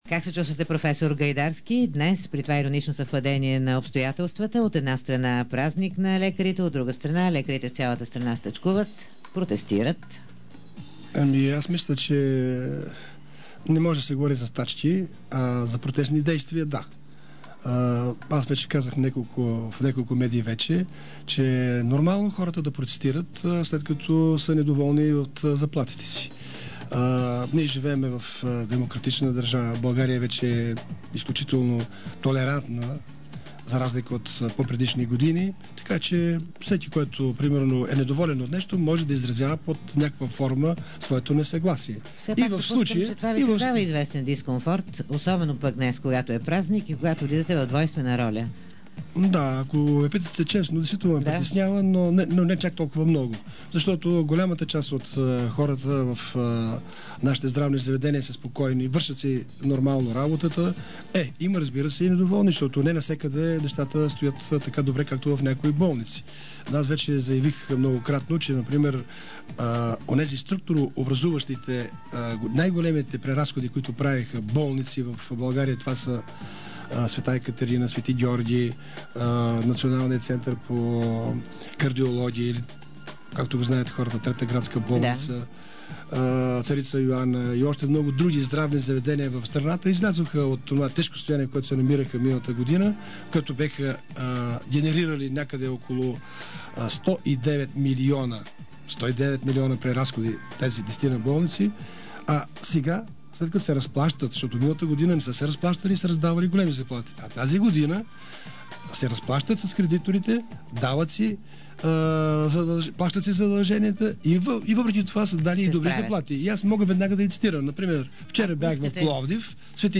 DarikNews audio: Проф. Радослав Гайдарски, министър на здравеопазването за протестите на медиците и дълговете на болниците в интервю за предаването „Дарик кафе”.